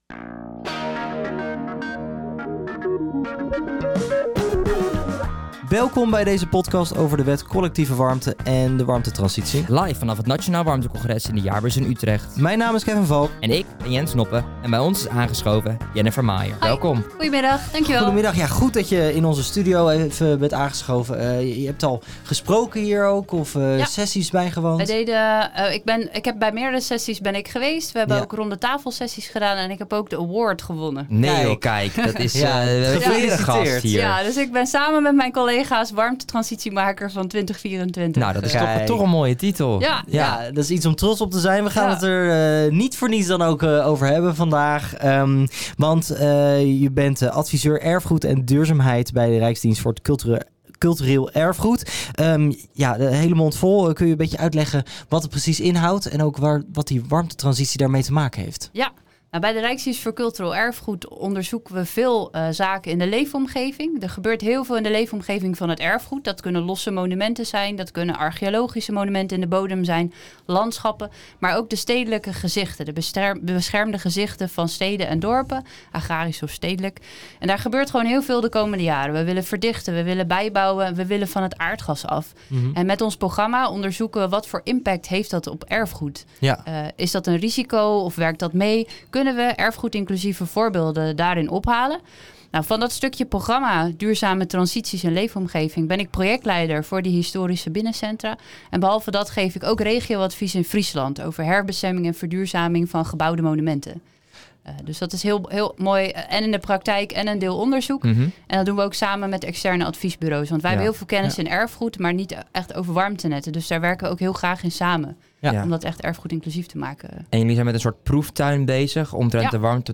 Live vanaf de beursvloer van het Nationaal Warmte Congres op 17 oktober zijn er podcast interviews afgenomen met diverse stakeholders binnen de warmtesector.